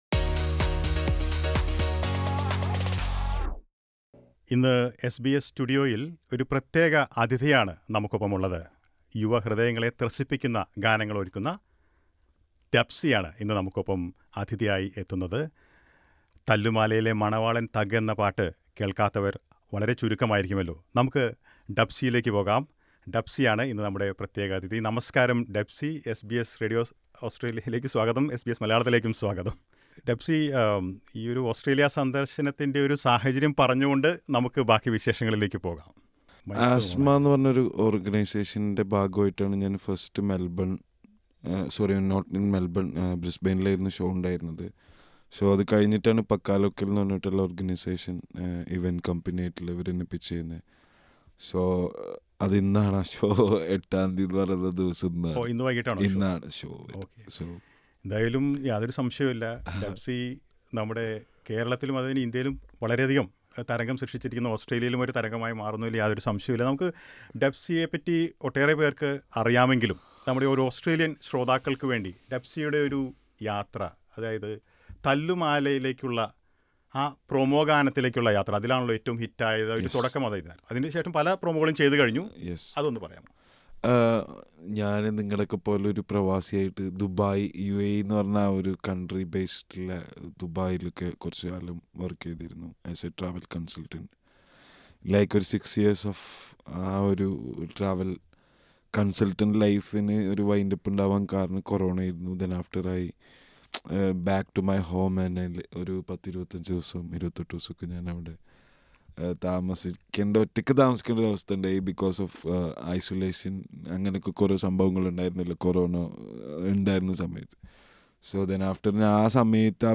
കലാകാരന്മാർക്ക് ആധുനിക യുഗത്തിൽ തൊഴിൽ സാധ്യതകളേറെയാണെന്ന് യുവാക്കളെ ത്രസിപ്പിക്കുന്ന ഗാനങ്ങൾകൊണ്ട് തരംഗമായിരിക്കുന്ന ഡബ്സി എസ് ബി എസ് മലയാളത്തോട് പറഞ്ഞു. സംഗീതപരിപാടികള്‍ അവതരിപ്പിക്കാനായി ഓസ്‌ട്രേലിയയിലെത്തിയ ഡബ്‌സി, മെല്‍ബണിലെ എസ് ബി എസ് സ്റ്റുഡിയോയിലെത്തി. അഭിമുഖം കേൾക്കാം മുകളിലെ പ്ലെയറിൽ നിന്ന്.